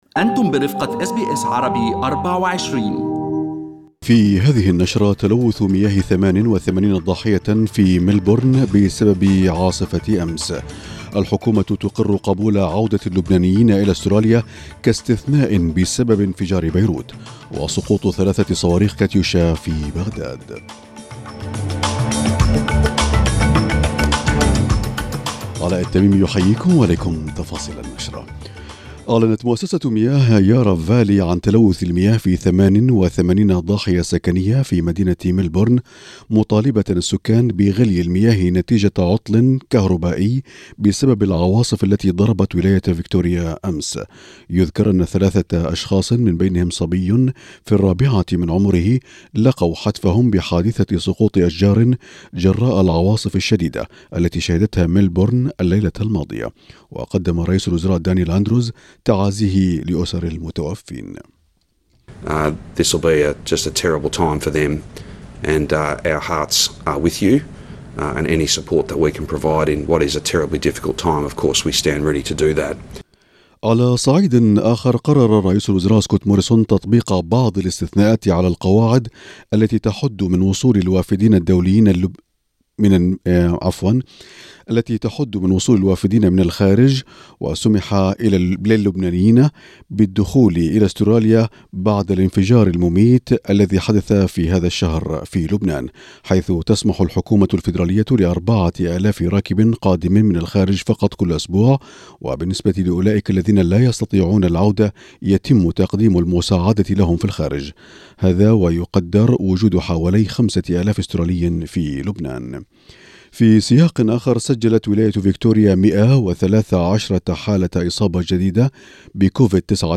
نشرة أخبار المساء 28/8/2020